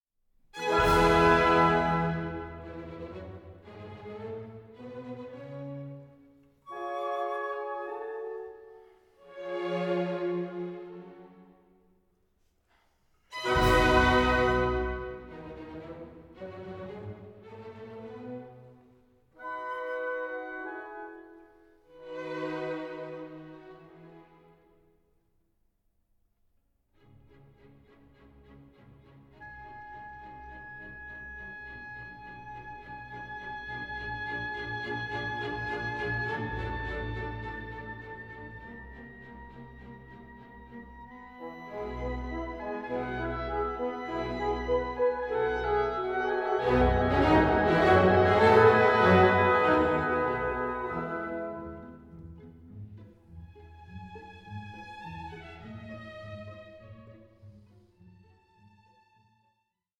ELEGANT, EXHILARATING, ELECTRIFYING!